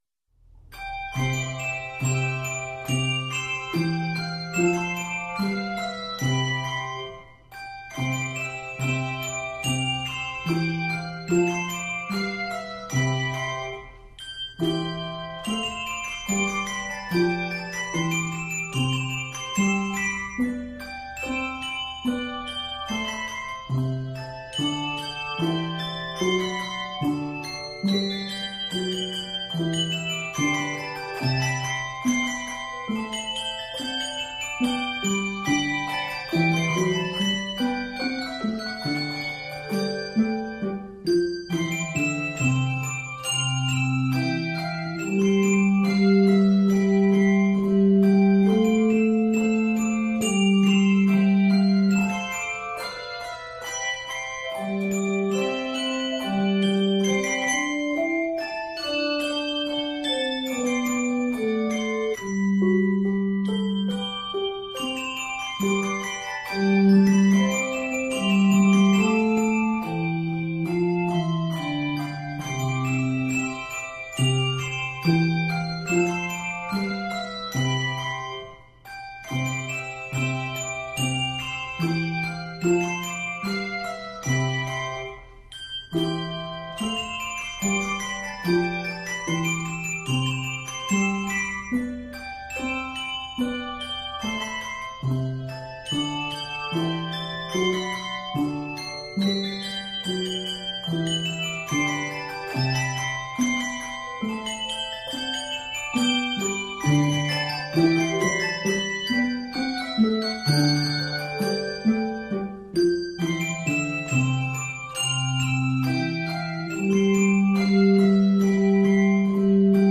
Scored in C Major